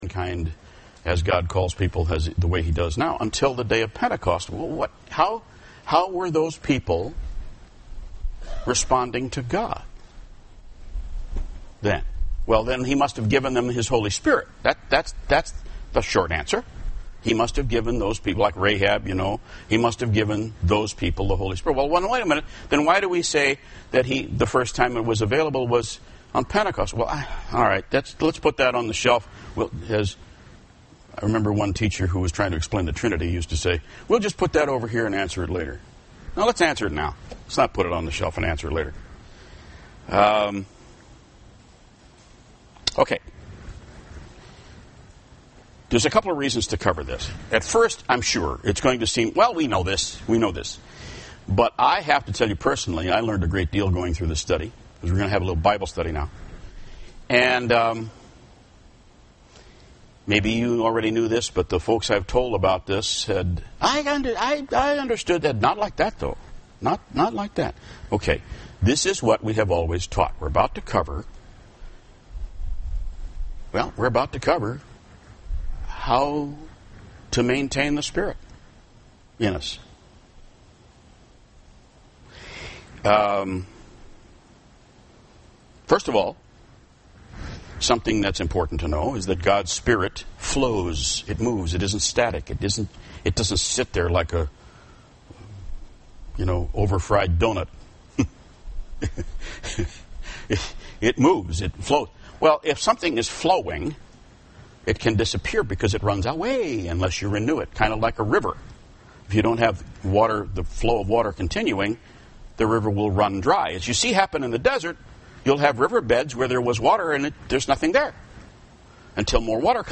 UCG Sermon Notes Intro: God’s Spirit flows, It is NOT static.